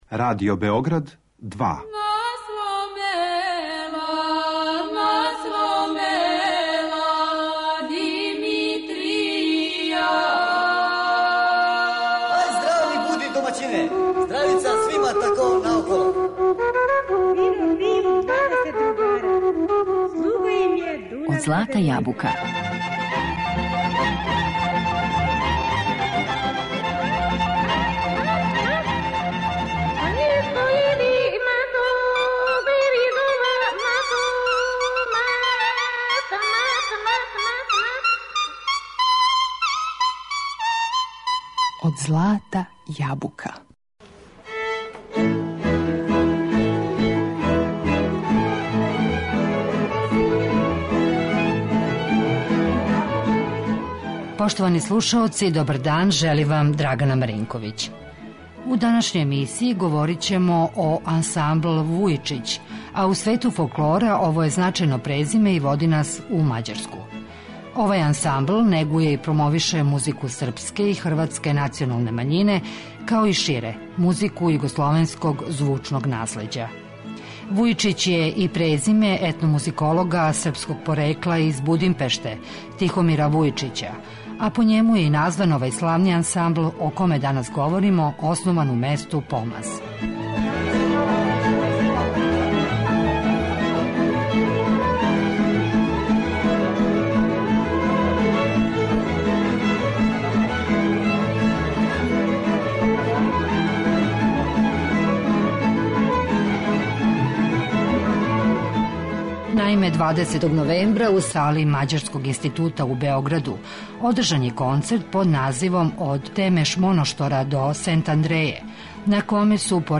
Овај ансамбл негује и промовише музику српске и хрватске националне мањине у Мађарској, као и шире - музику југословенског звучног наслеђа.